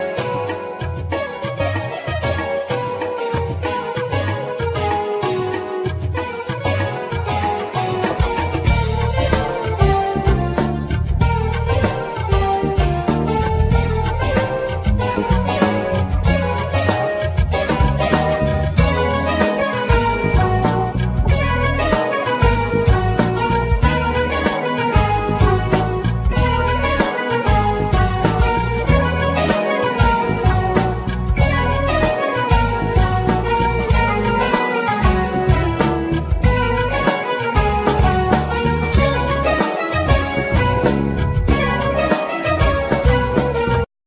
Guitar,Guitar Synth,Programming
Fretless Bass